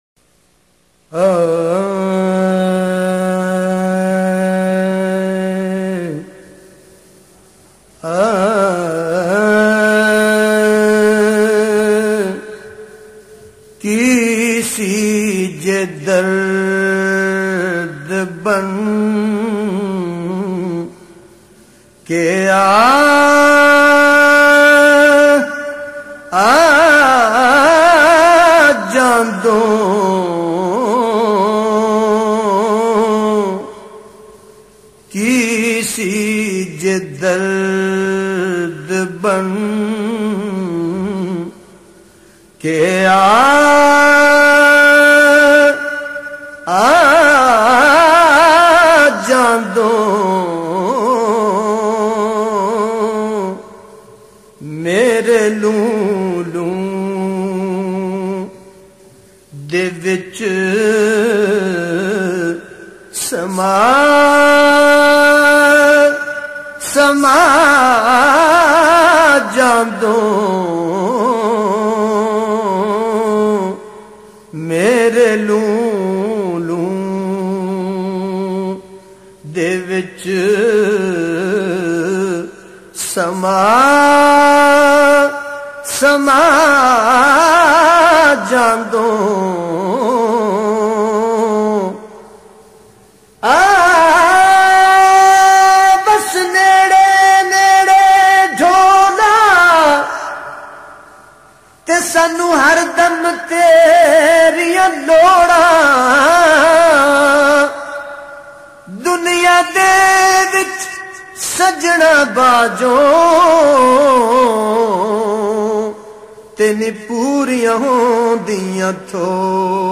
Ki Si Jey Dard Ban K naat audio